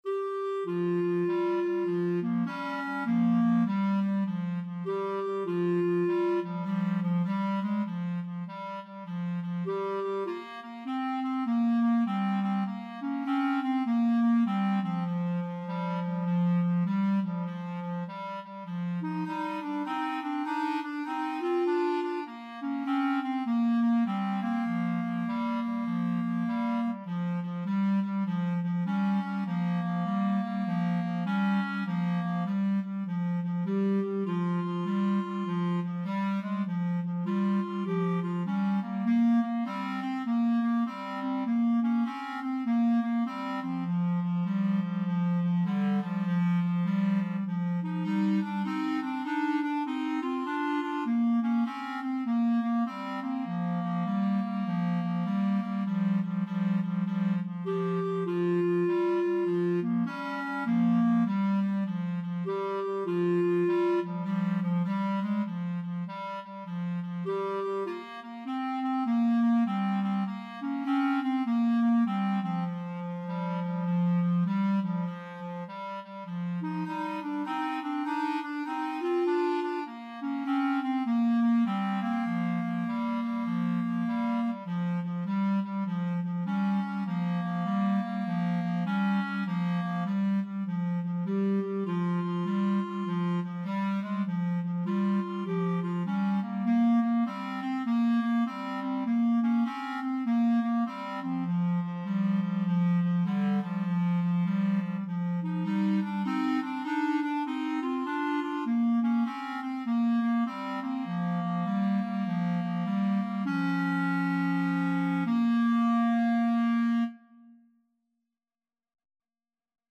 Free Sheet music for Clarinet Duet
See See Rider, also known as C.C. Rider or See See Rider Blues or Easy Rider is a popular American 12-bar blues song.
Bb major (Sounding Pitch) C major (Clarinet in Bb) (View more Bb major Music for Clarinet Duet )
4/4 (View more 4/4 Music)
Moderato
Clarinet Duet  (View more Easy Clarinet Duet Music)